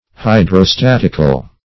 Hydrostatic \Hy`dro*stat"ic\, Hydrostatical \Hy`dro*stat"ic*al\,